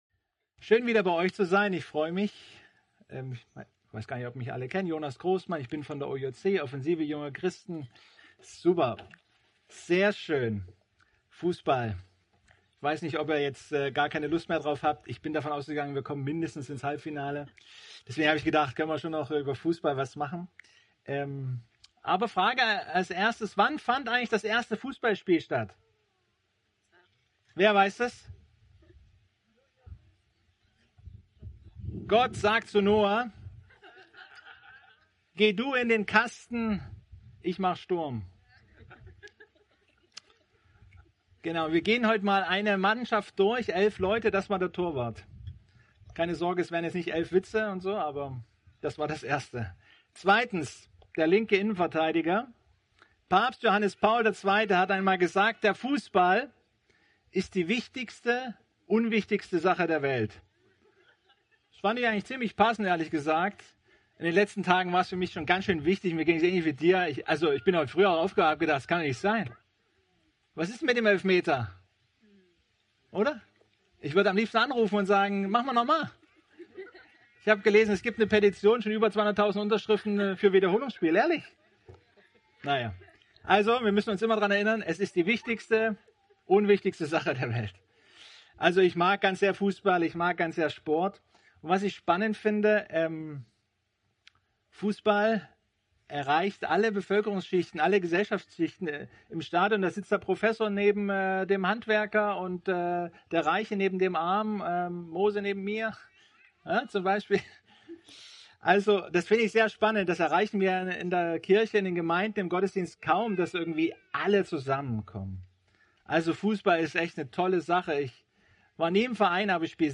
Eine predigt aus der serie "GreifBar+."